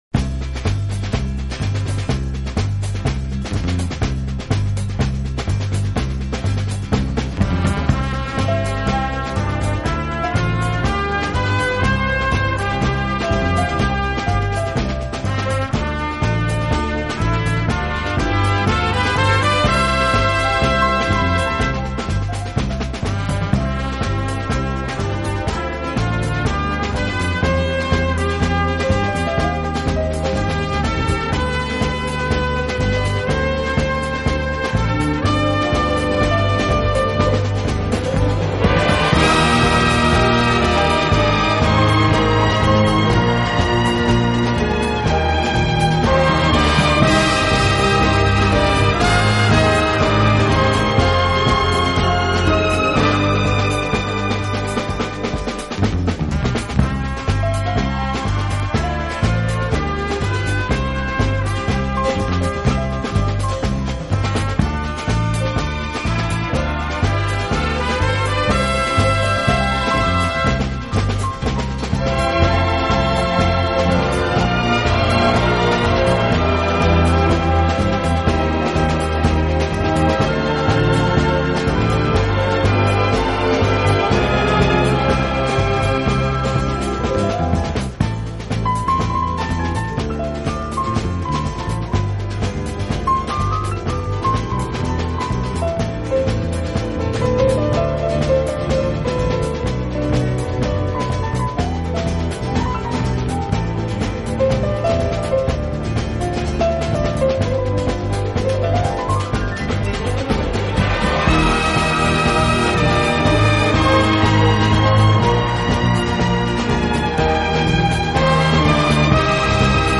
音乐风格：soundtrack / pop / easy listening